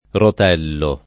rot$llo] s. m. — voce romagn. per «rotolo di tela»: Ho due lenzuola nuove: anche un rotello [